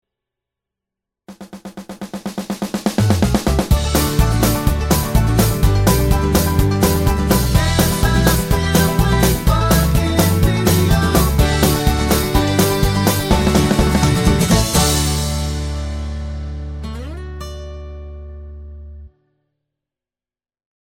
Clean Backing Vocals Comedy/Novelty 1:11 Buy £1.50